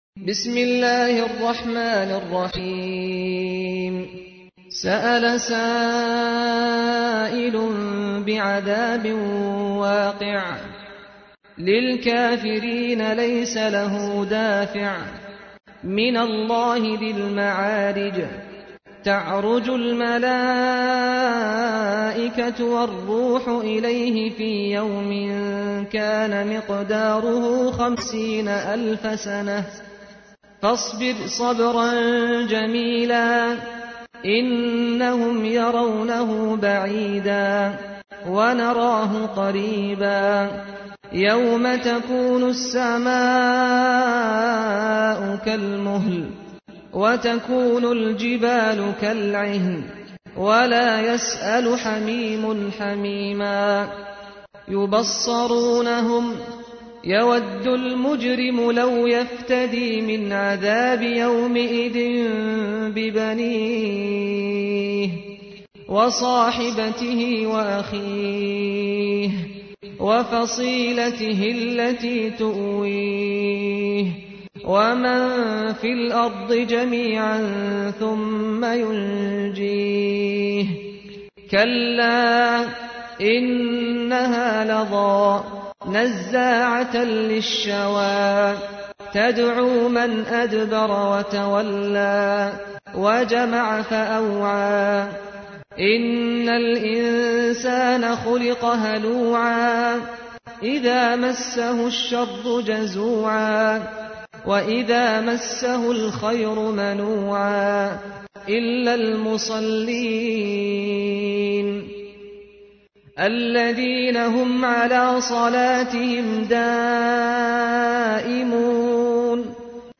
تحميل : 70. سورة المعارج / القارئ سعد الغامدي / القرآن الكريم / موقع يا حسين